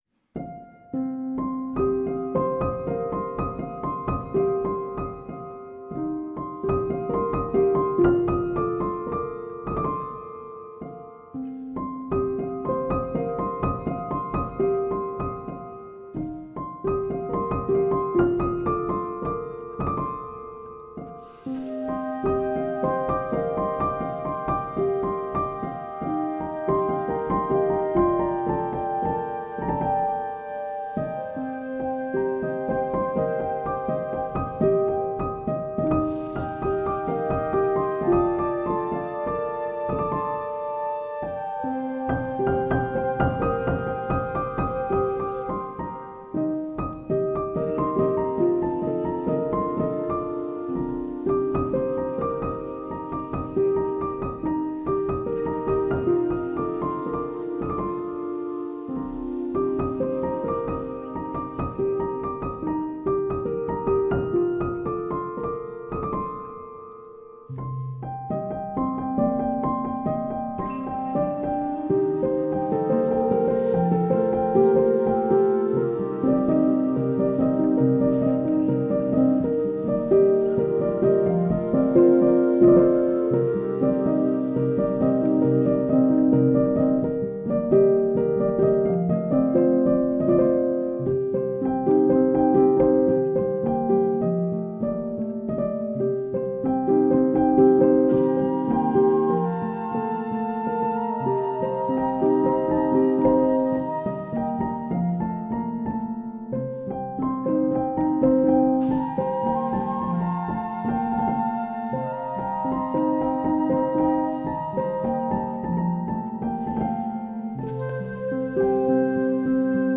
An instrumental EP of 7 piano pieces